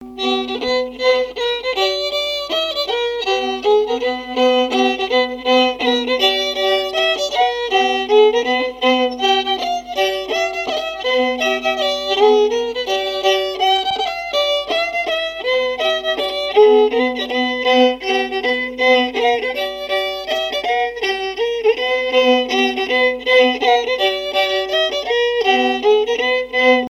Chants brefs - A danser
danse : mazurka
répertoire musical au violon
Pièce musicale inédite